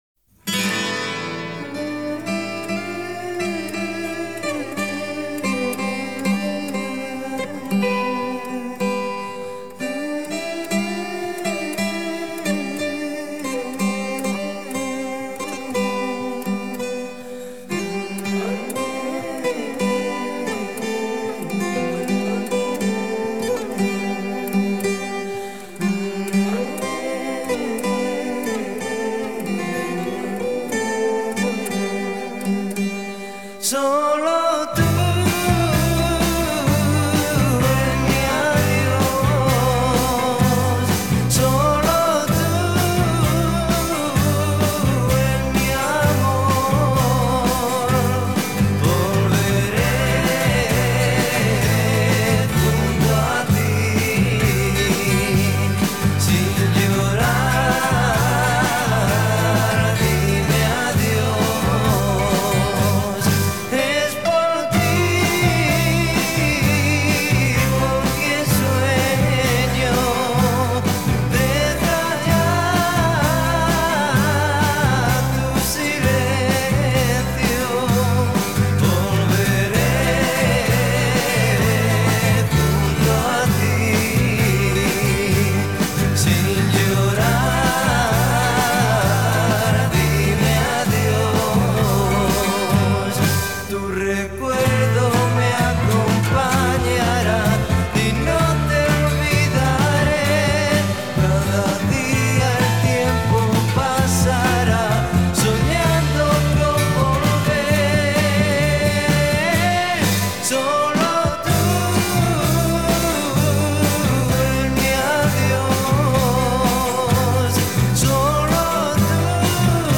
Un rock progresivo y sinfónico del año 1969.
Con sus gotas de psicodelia